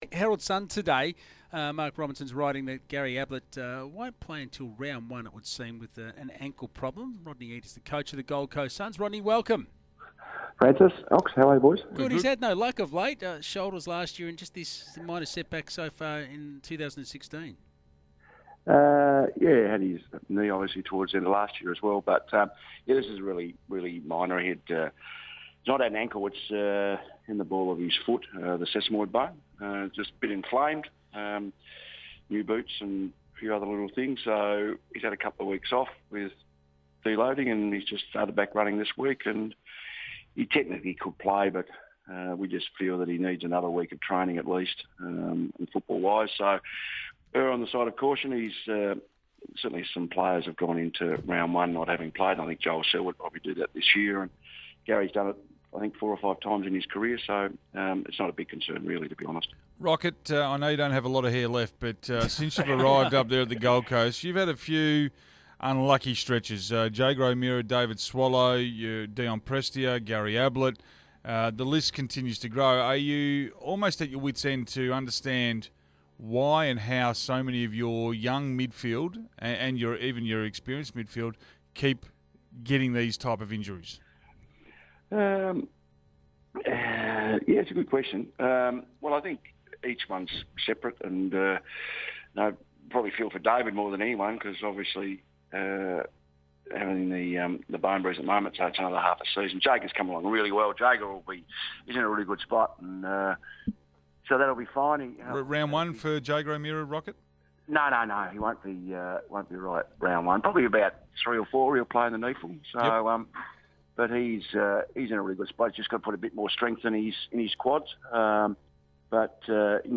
Senior Coach Rodney Eade joined SEN Breakfast hosts Francis Leach and David Schwarz on March 8.